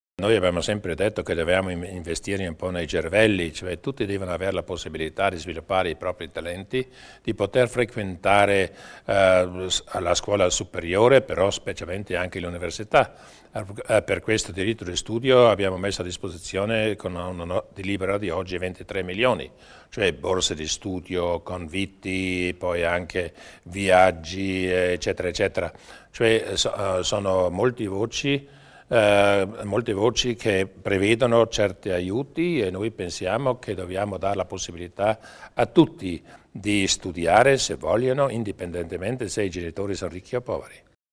Il Presidente Durnwalder spiega l'impegno per il sostegno al diritto allo studio